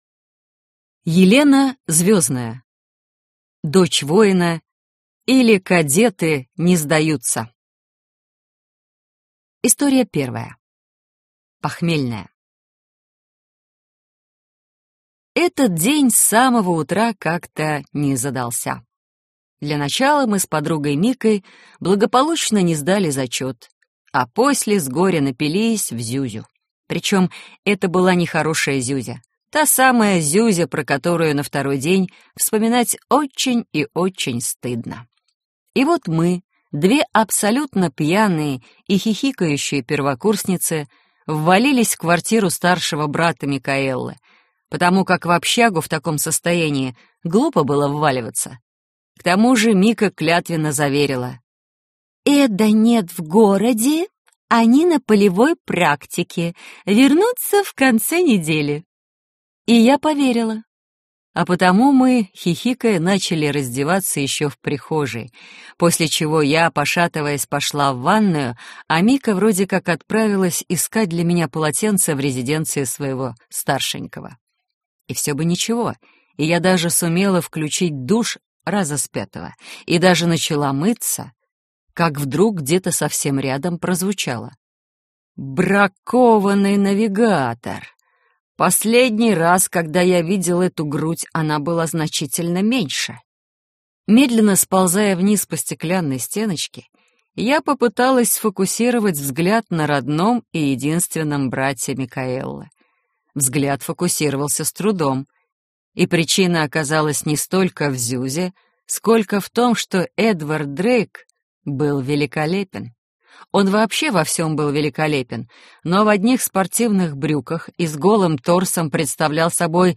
Аудиокнига Дочь воина, или Кадеты не сдаются - купить, скачать и слушать онлайн | КнигоПоиск